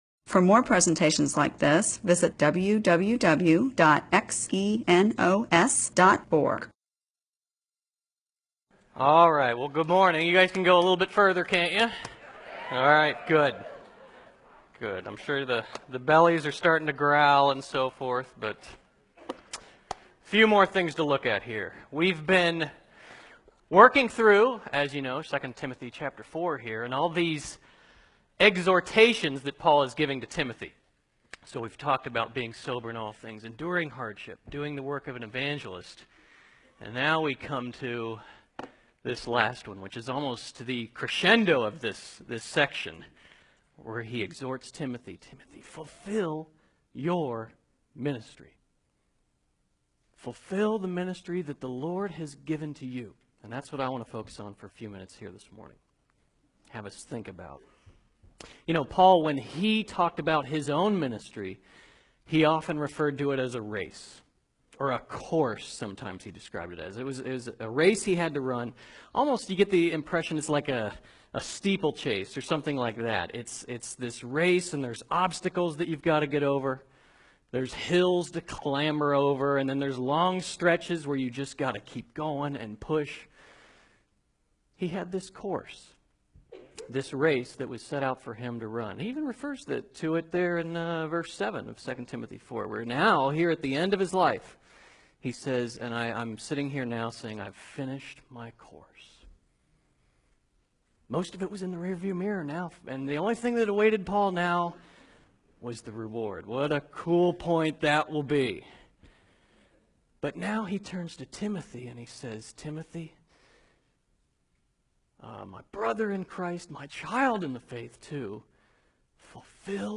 MP4/M4A audio recording of a Bible teaching/sermon/presentation about 2 Timothy 4:5.